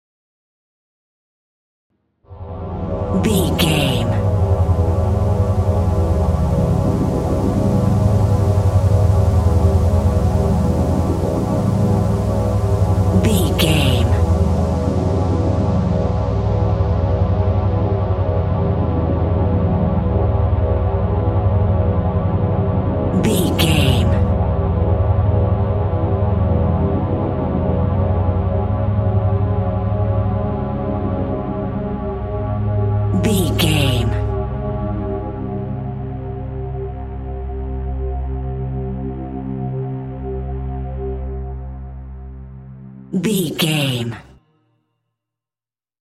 Atonal
scary
tension
ominous
dark
haunting
eerie
ambience